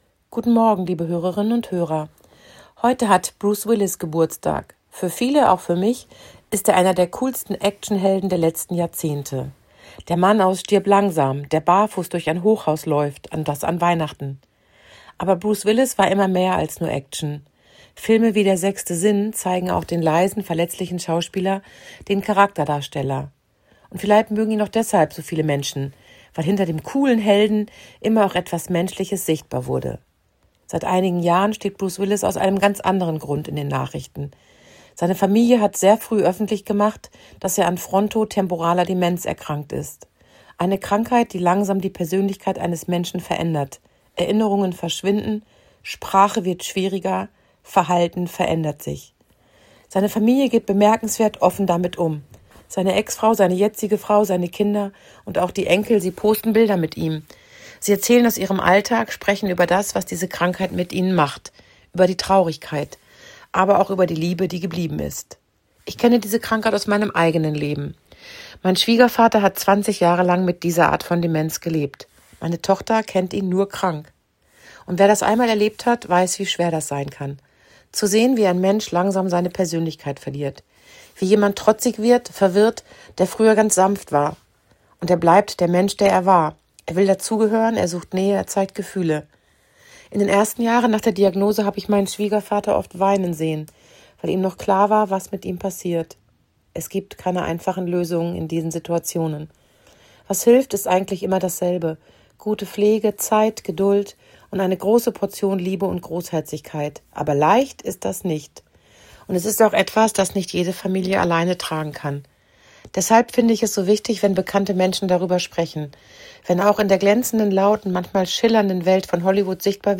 Hameln-Pyrmont: Radioandacht vom 19. März 2026 – radio aktiv